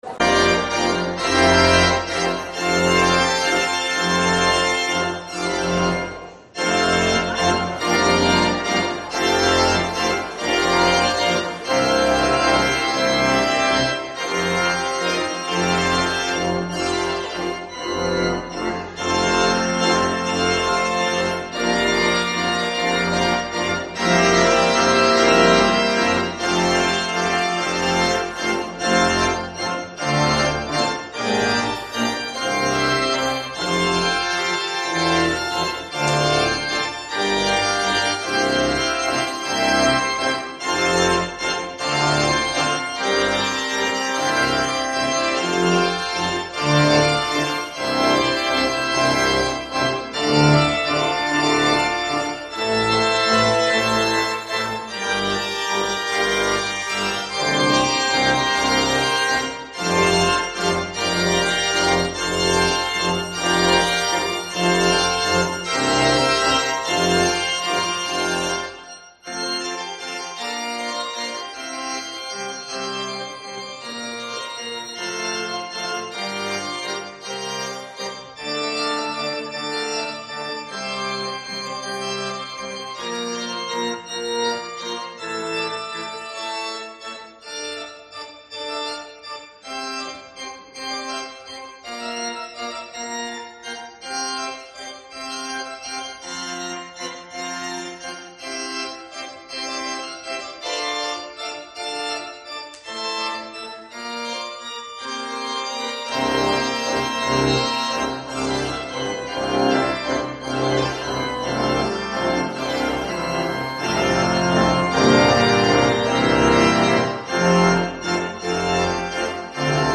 Gottesdienst vom 16. Juli